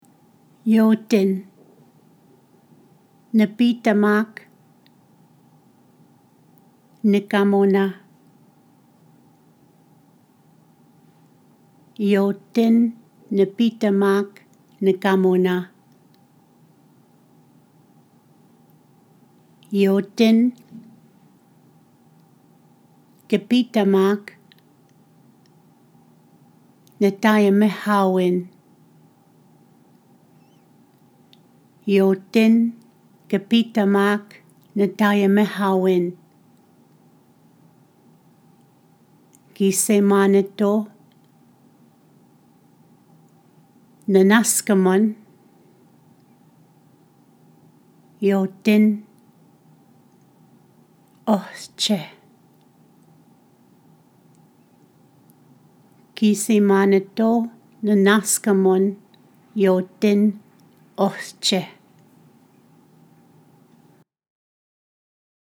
MP3 pronunciation file for Yotin
Yo╠etin-pronunciation.mp3